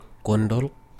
GOON-doolk
If you know IPA [ gʊndulk ]